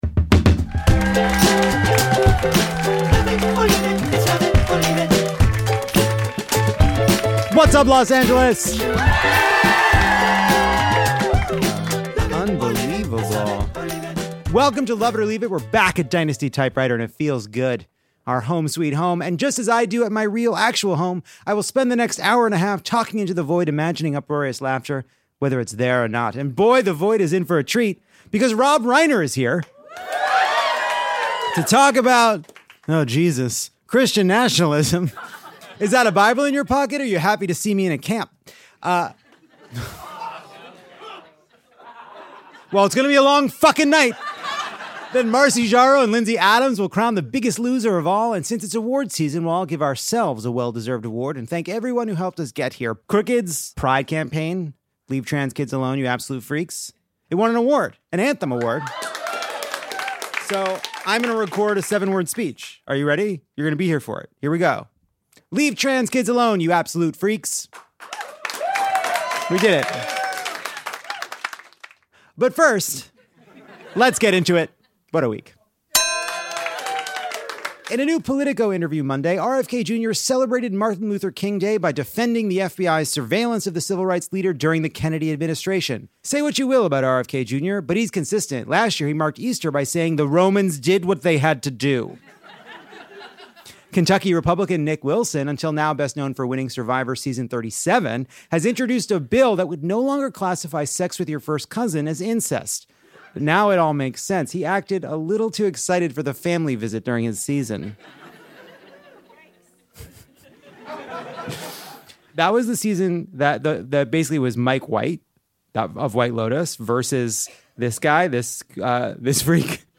Lovett or Leave It makes its glorious return to Dynasty Typewriter in beautiful Los Angeles, California. Rob Reiner grades the monologue and shares his thoughts on everything from Christian nationalism to polyamory.